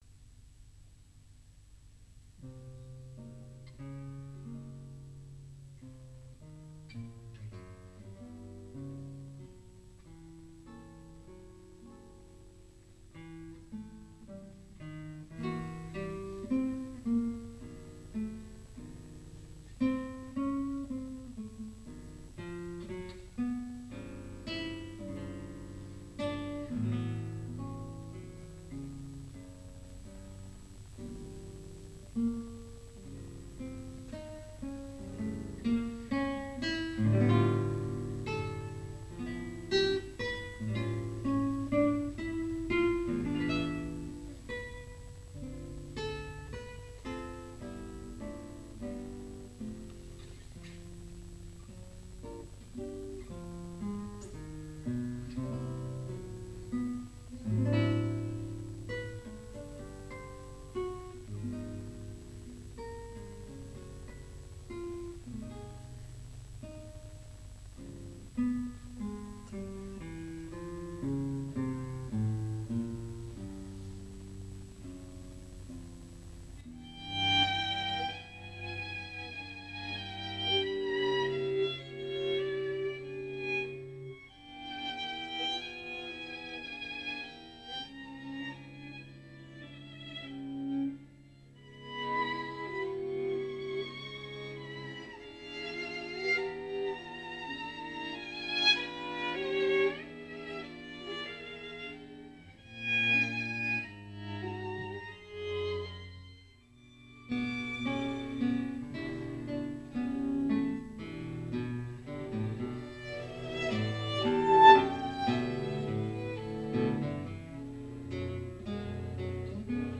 Chitarra e quartetto d'archi
21/03/2012 10:58 Archiviato in: Live recording
Salone delle Manifestazioni del Palazzo regionale, Novembre 1993
violini
viola
violoncello
chitarra
Eugène Bozza (1905- 1991) - Concertino da camera pour guitare et quatuor à cordes (1969) dedicato a Konrad Ragossnig
Allegretto